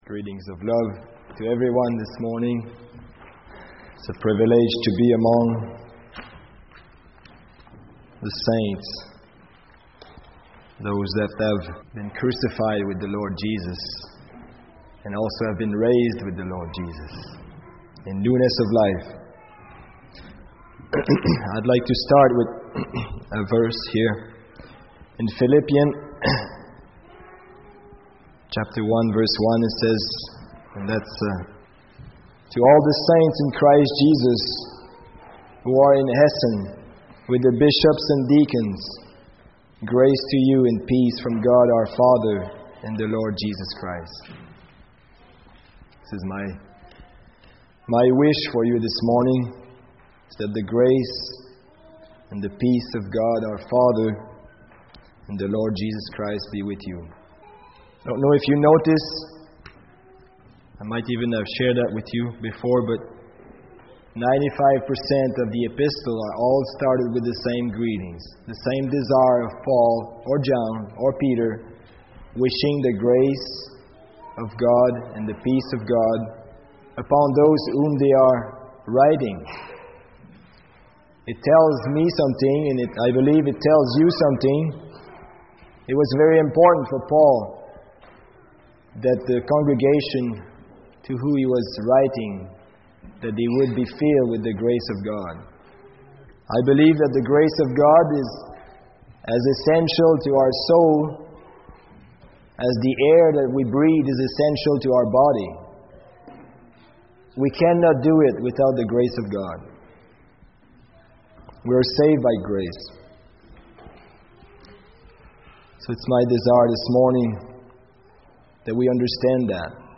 Series: Sunday Morning Sermon Service Type: Sunday Morning